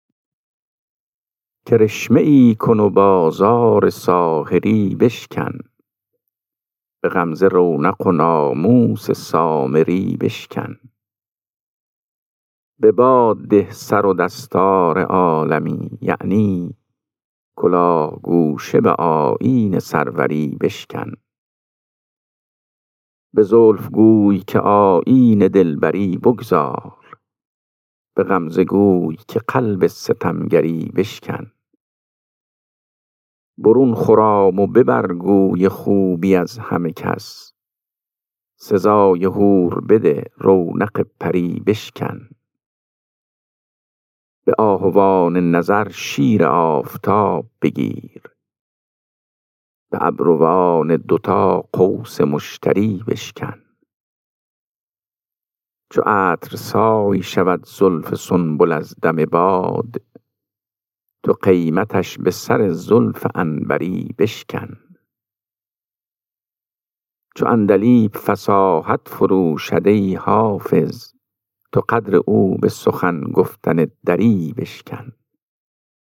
خوانش غزل شماره 399 دیوان حافظ